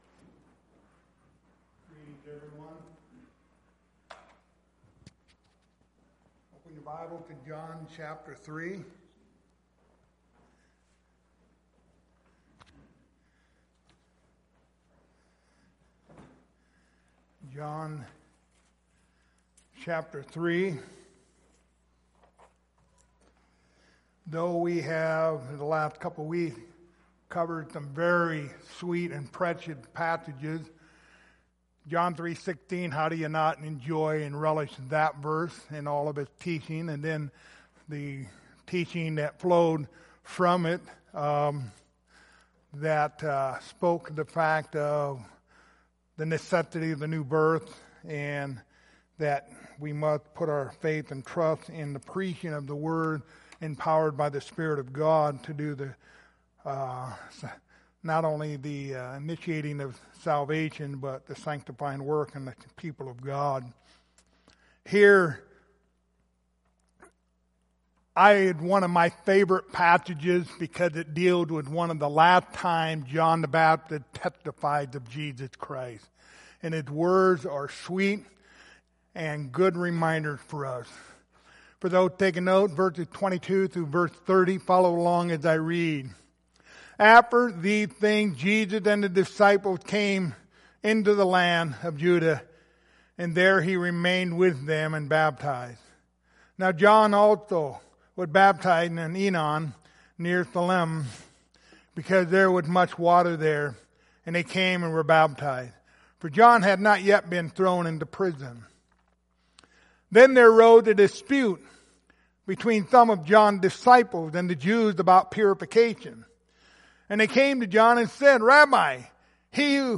Passage: John 3:22-30 Service Type: Wednesday Evening Topics